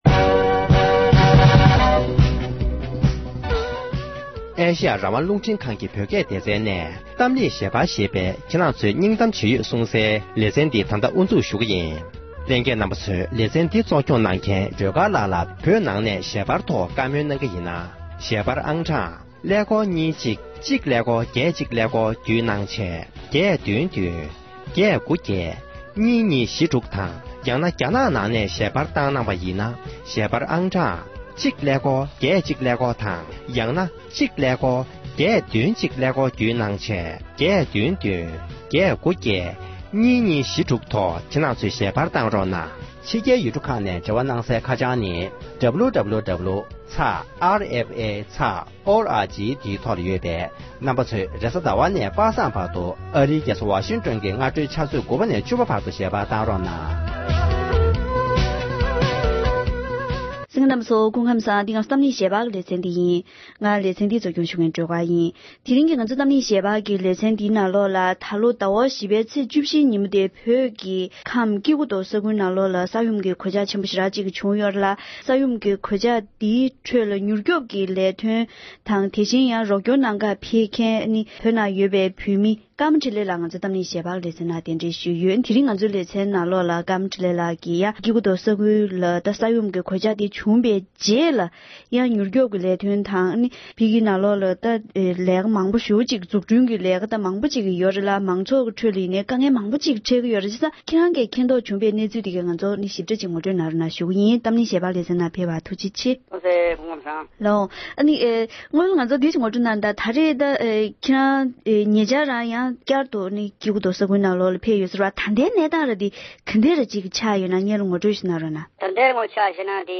ཉེ་ཆར་སྐྱེ་རྒུ་མདོར་ཕེབས་མྱོང་མཁན་གྱི་བོད་མི་ཞིག་དང་ལྷན་དུ་བཀའ་མོལ་ཞུས་པར་གསན་རོགས༎